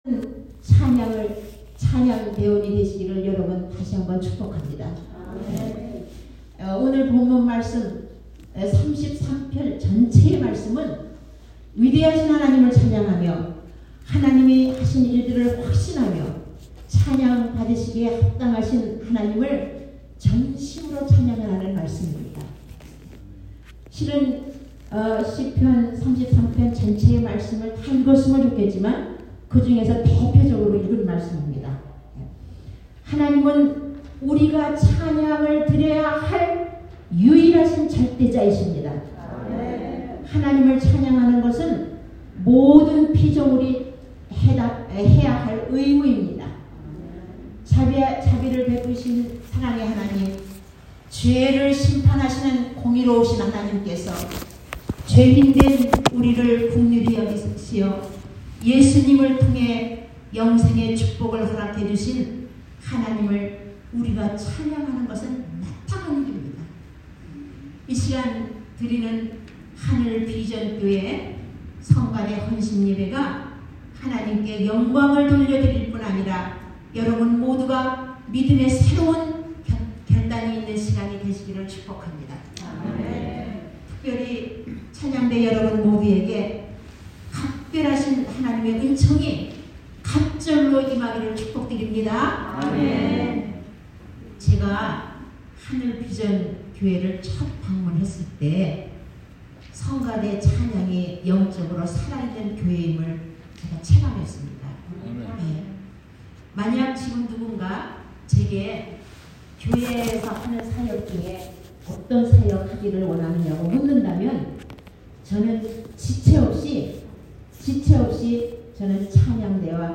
말씀
특별예배 Special Worship